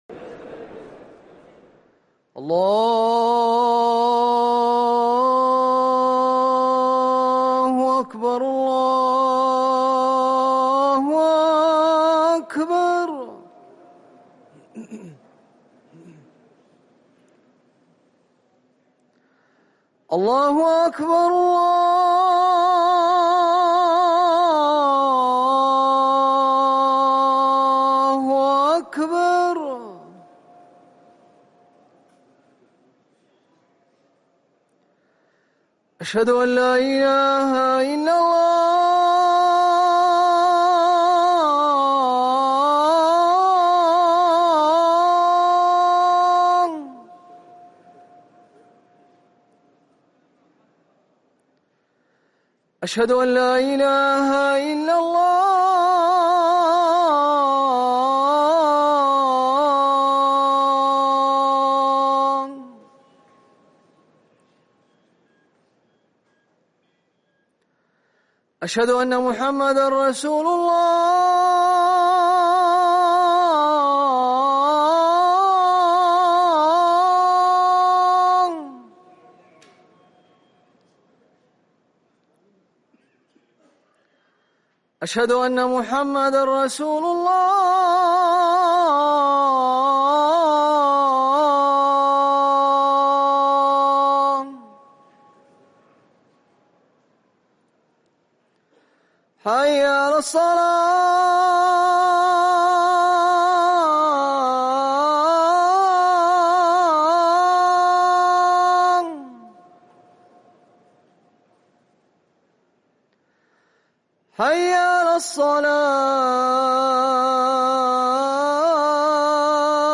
اذان العشاء